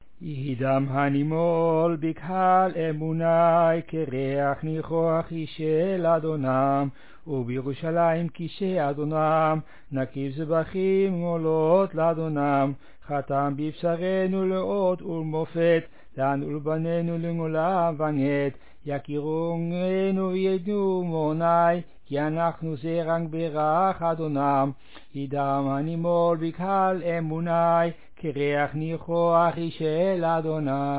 All sing
reconstructed melody